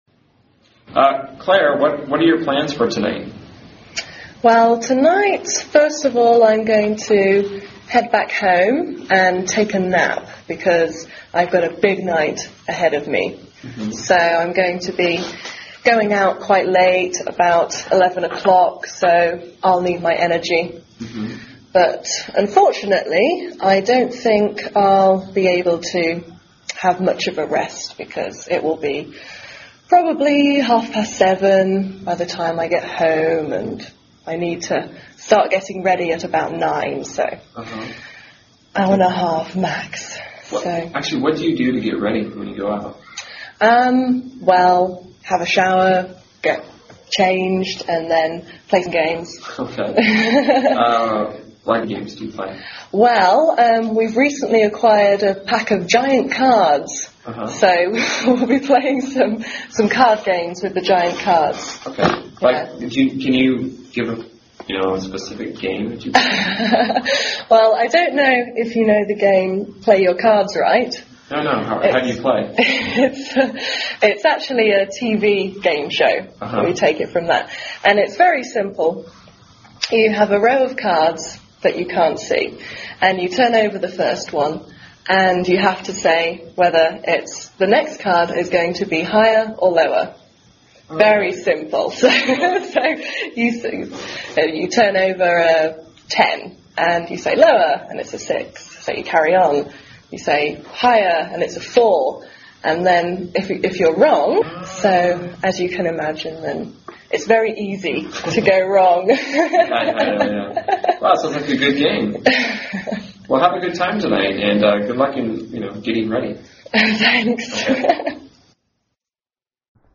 在线英语听力室原版英语对话1000个:056 Night Out的听力文件下载,原版英语对话1000个,英语对话,美音英语对话-在线英语听力室